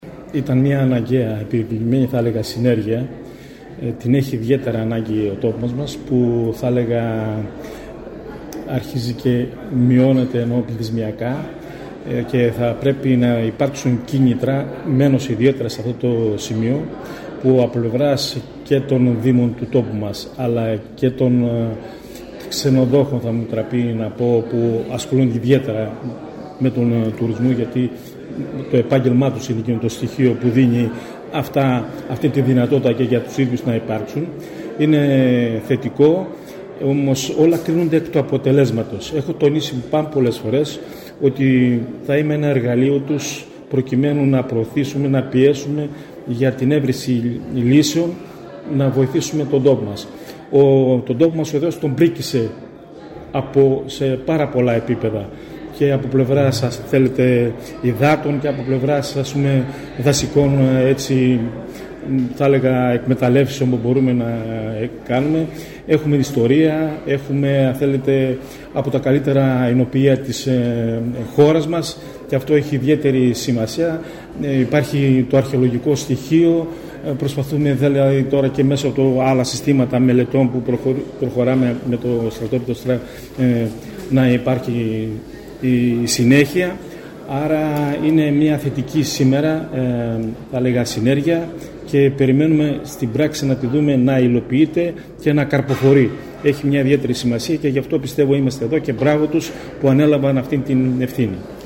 Κυριαζίδης Δημήτρης – Βουλευτής Ν.Δ. Δράμας